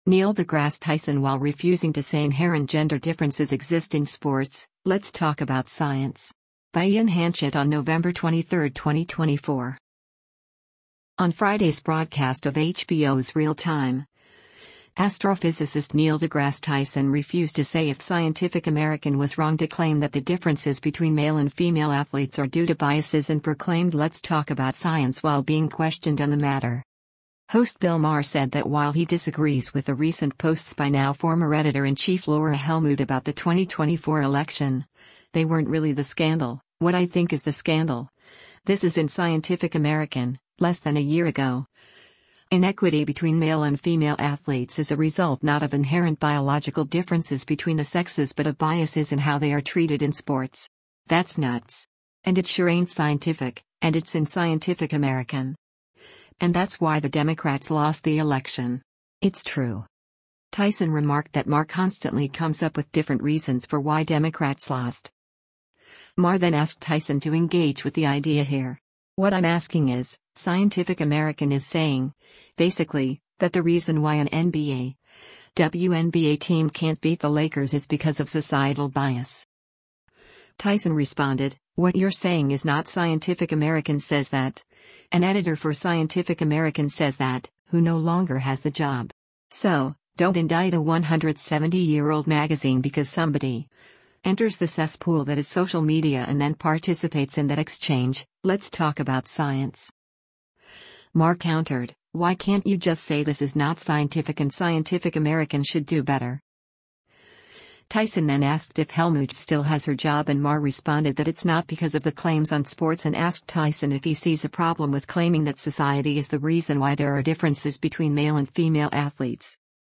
On Friday’s broadcast of HBO’s “Real Time,” astrophysicist Neil deGrasse Tyson refused to say if Scientific American was wrong to claim that the differences between male and female athletes are due to biases and proclaimed “Let’s talk about science” while being questioned on the matter.